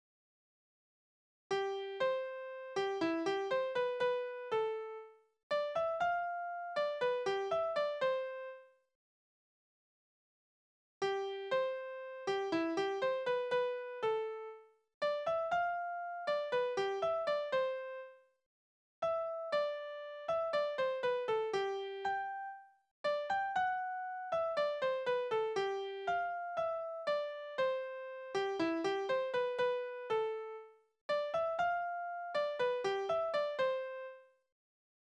Tonart: C-Dur
Taktart: C (4/4)
Tonumfang: kleine Dezime
Besetzung: vokal